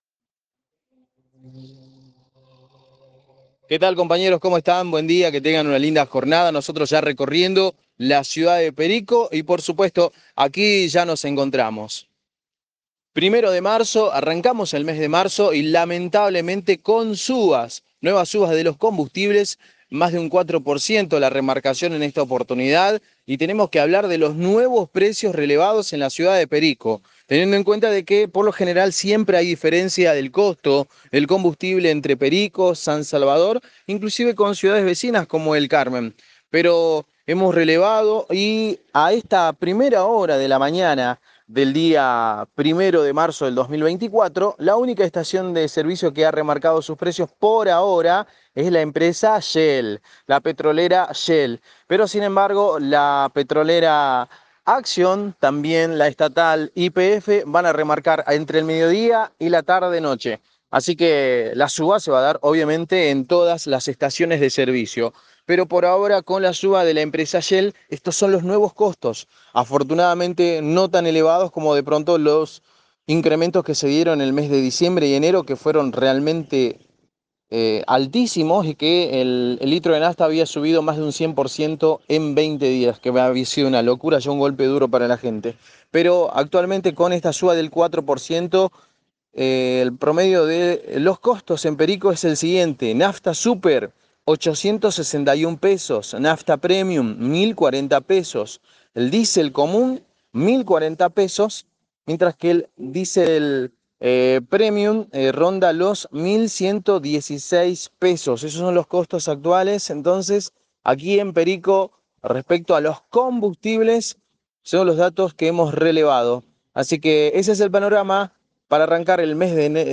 - Informe